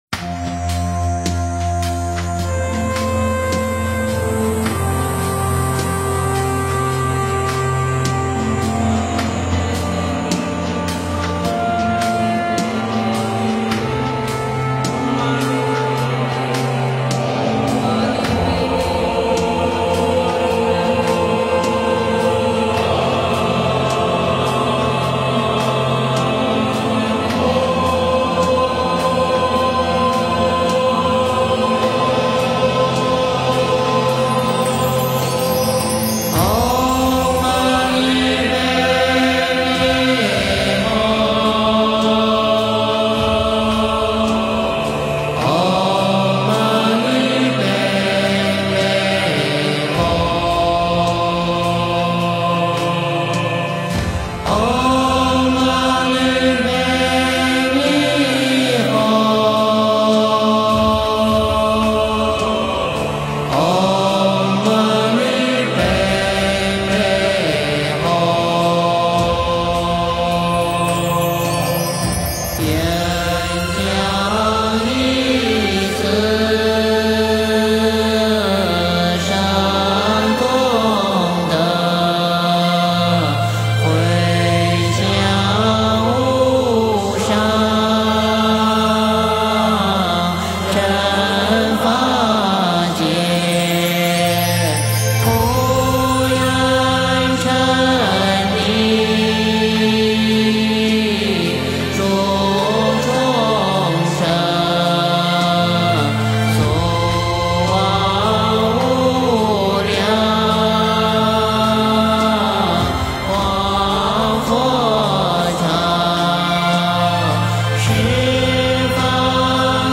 诵经
佛音 诵经 佛教音乐 返回列表 上一篇： 观音赞 下一篇： 绿度母心咒 相关文章 观音行愿曲(童音版